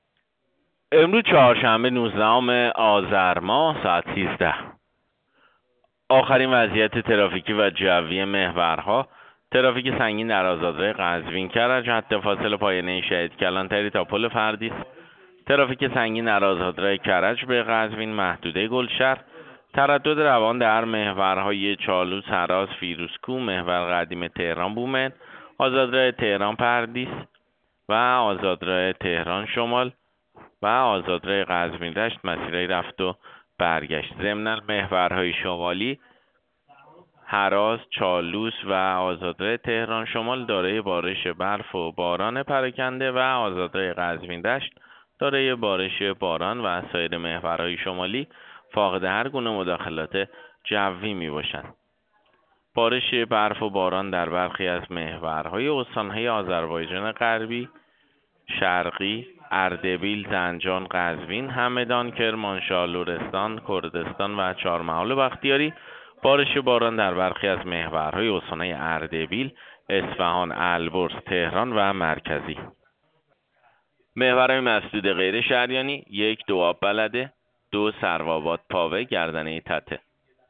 گزارش رادیو اینترنتی از آخرین وضعیت ترافیکی جاده‌ها ساعت ۱۳ نوزدهم آذر؛